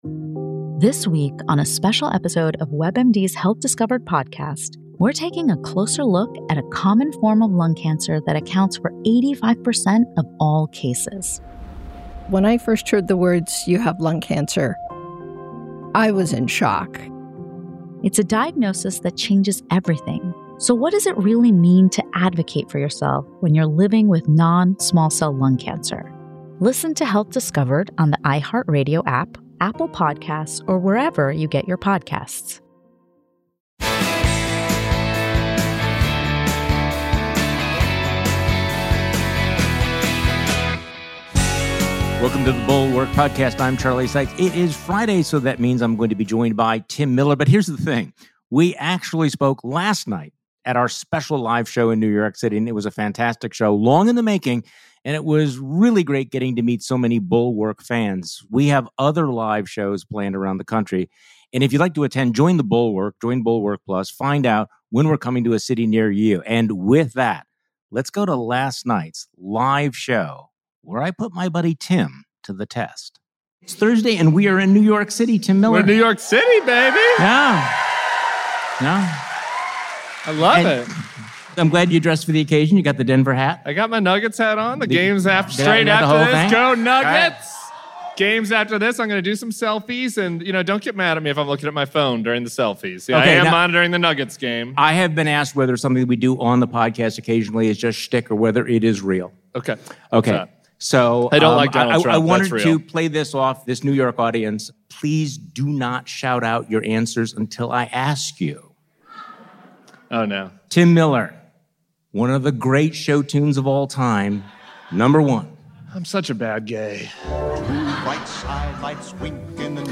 The Bulwark: Live from New York
Charlie Sykes and Tim Miller held court on the Upper West Side Thursday night, weighing in on Rudy's medical experiments on himself, Disney's humiliation of DeSantis, Trump's legal setbacks in his own hometown, and more. Plus, Charlie and Tim play "Name That Musical" for your weekend pod.